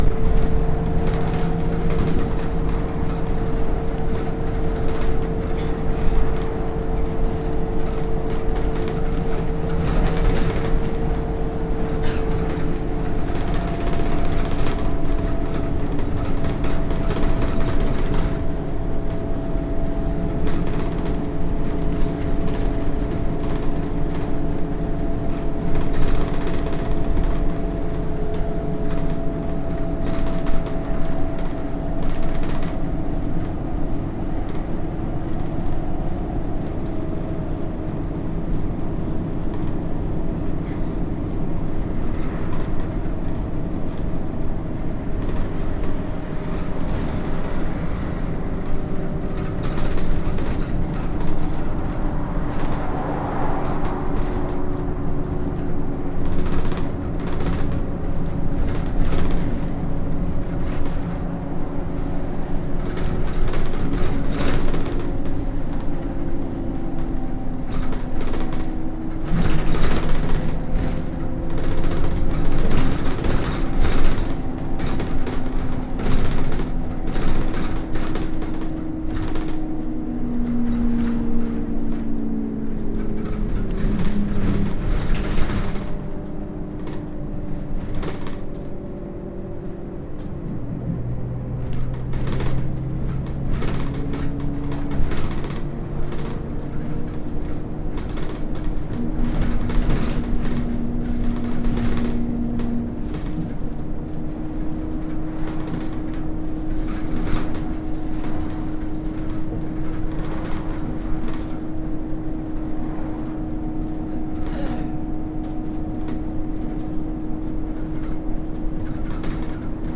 Very rattly Bus Boo, with inpatient driver towards the end